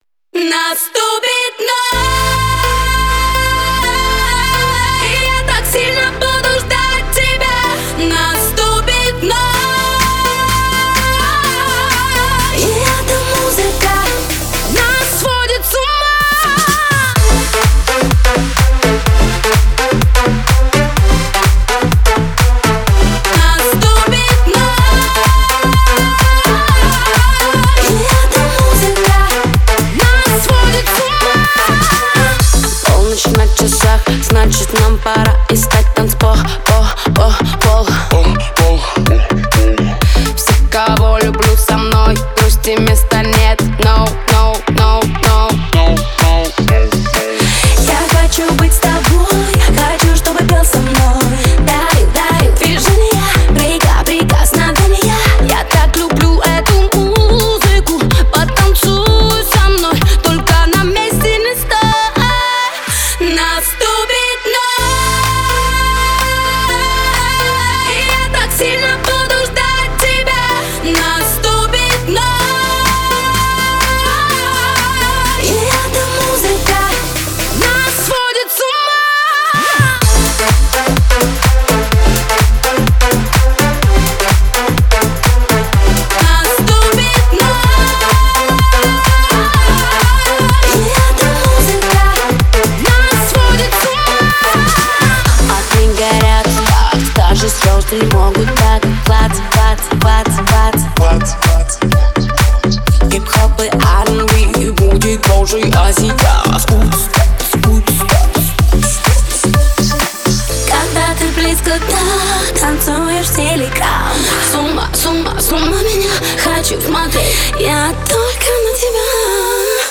Speed up remix